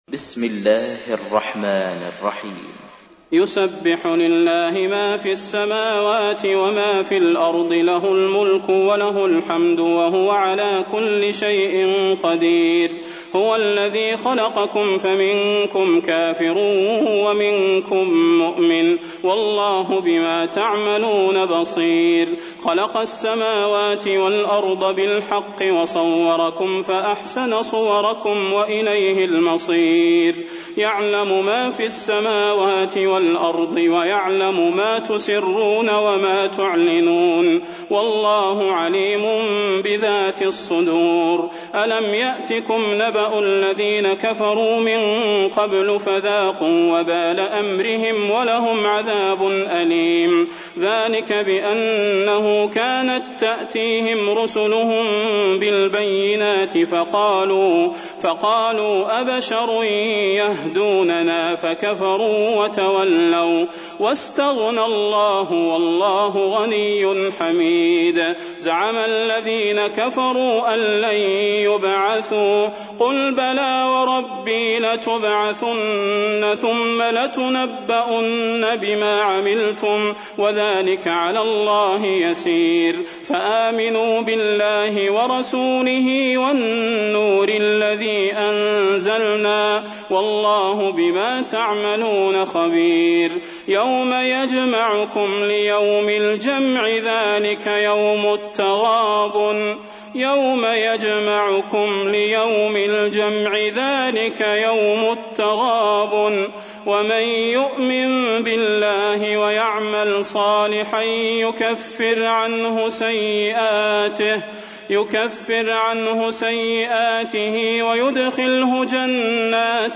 تحميل : 64. سورة التغابن / القارئ صلاح البدير / القرآن الكريم / موقع يا حسين